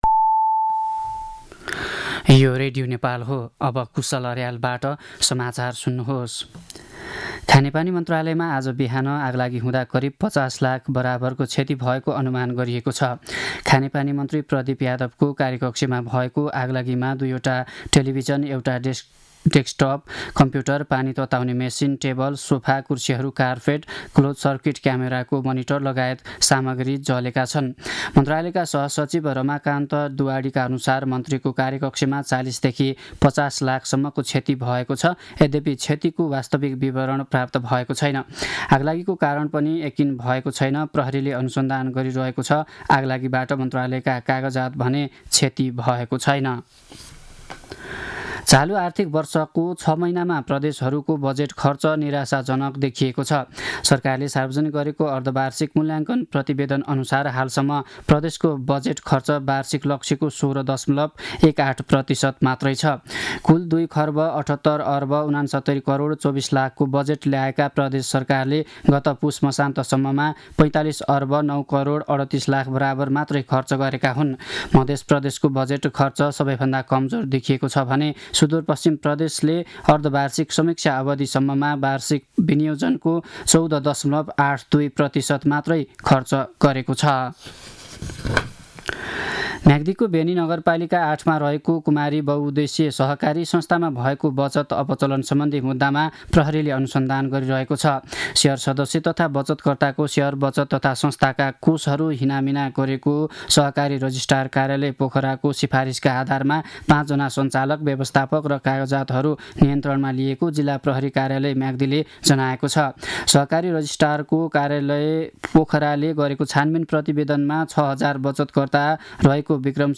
दिउँसो १ बजेको नेपाली समाचार : २७ माघ , २०८१